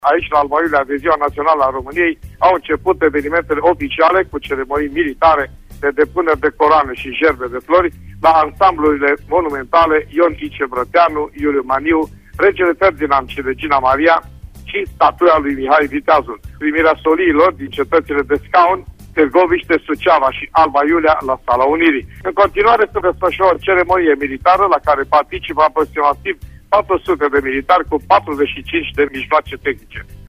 400 de militari şi 45 de mijloace tehnice sunt pregătite să treacă prin fața mulțimii, pe Bulevardul 1 Decembrie 1918. Aflăm amănunte de la fața locului